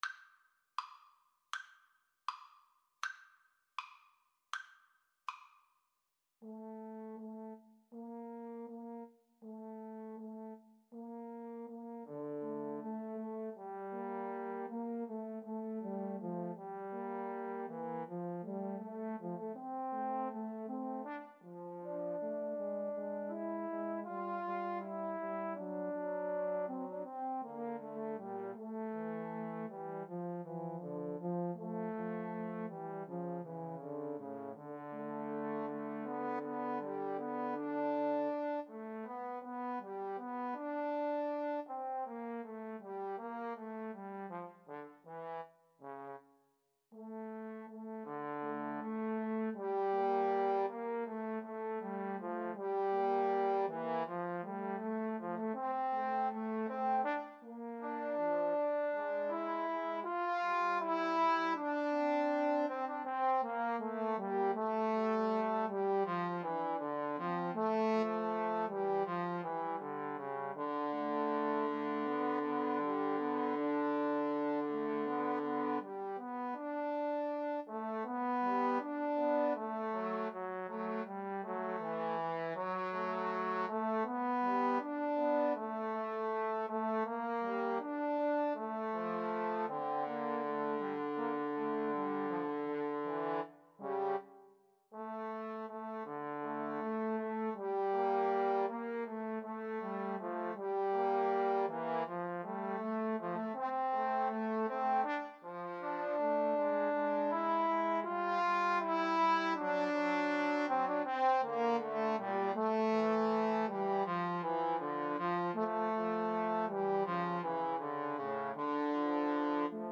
~ = 100 Andante